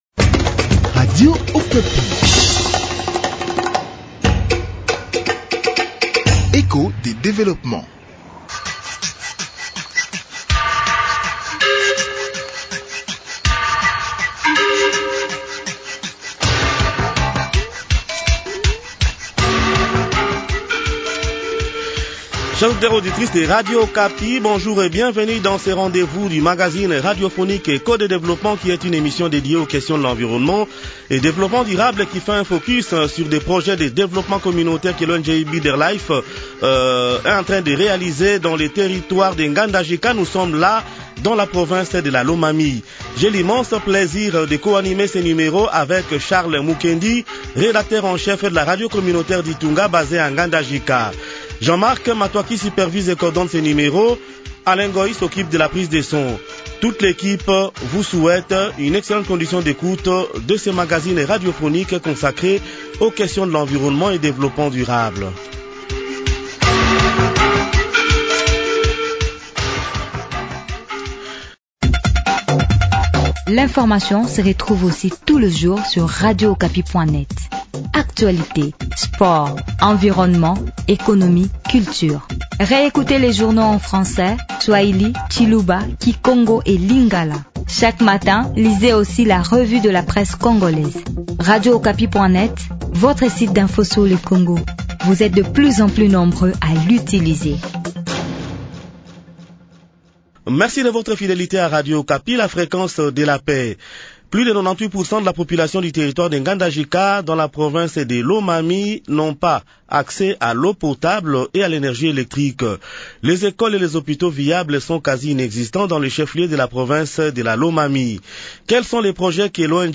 Réputé comme étant le grand grenier agricole dans l’espace Kasaï, Ngandajika fait face de nos jours à des nombreux défis qui freinent son développement, selon la population locale, interviewée lors d’un voyage de presse organisé par l’ONG Better Life qui a des projets dans le secteur de l’agriculture dans cette région.